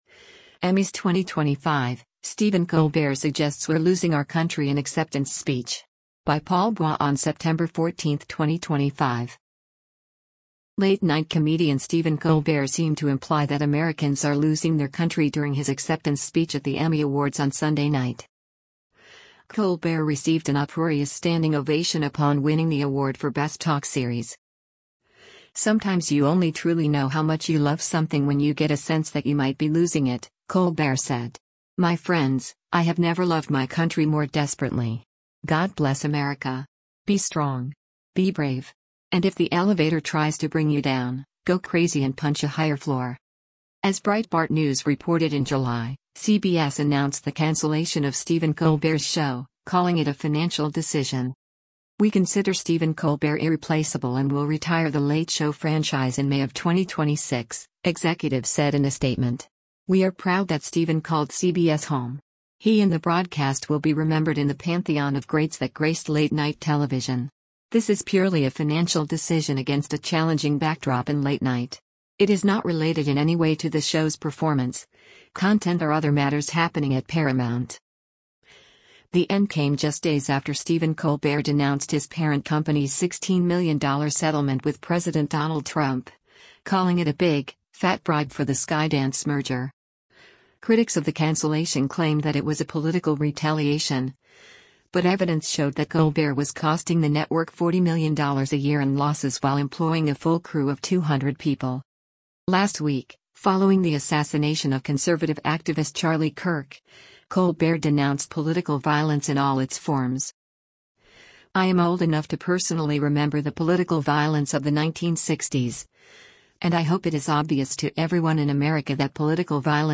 Late-night comedian Stephen Colbert seemed to imply that Americans are “losing” their country during his acceptance speech at the Emmy Awards on Sunday night.
Colbert received an uproarious standing ovation upon winning the award for Best Talk Series.